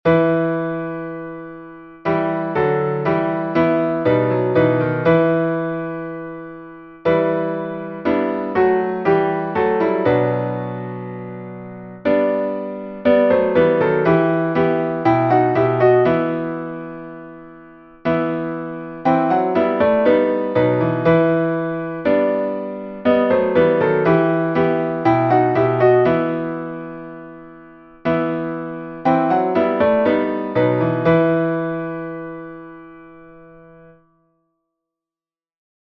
Key: e minor